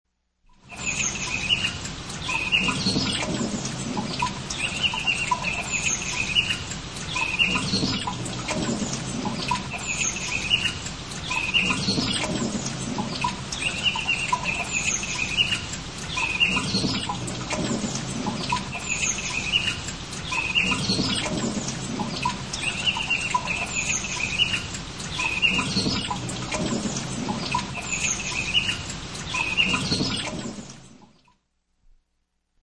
Звуки природы [130кб]